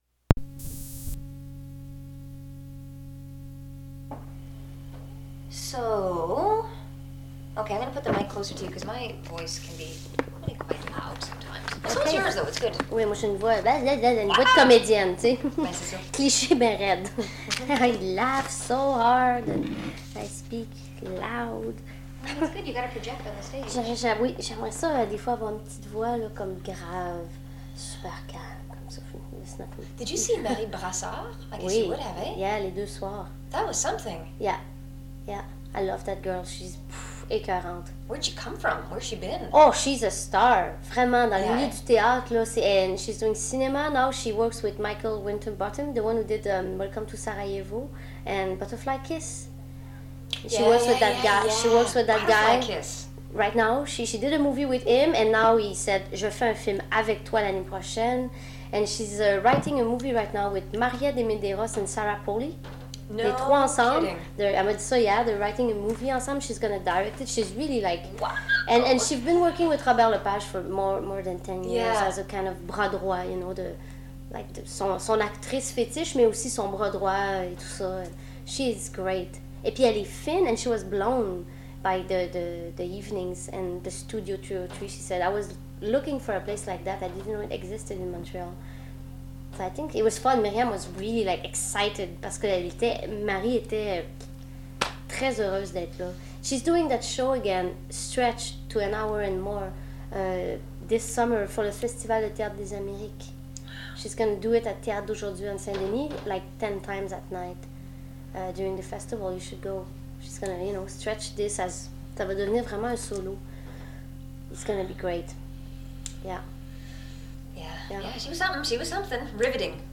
The Dykes on Mykes radio show was established in 1987.